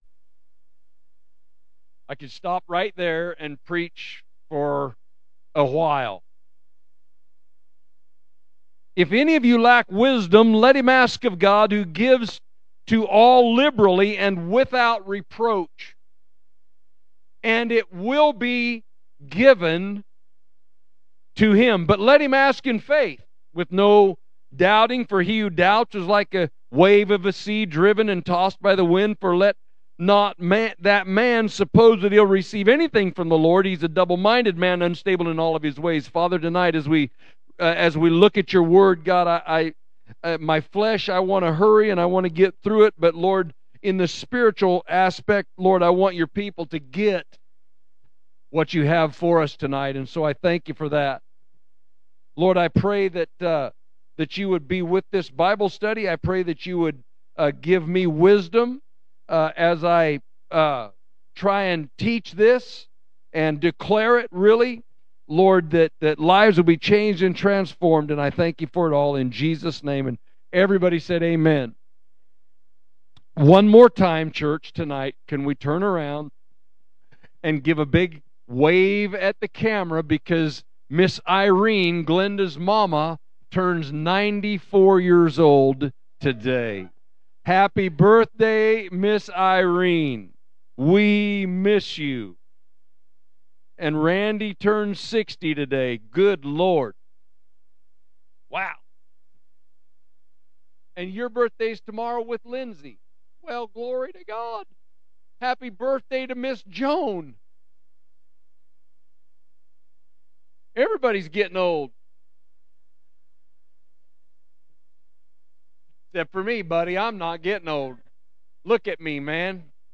James Week 7 – Midweek Service March 4, 2026
Recent Sermons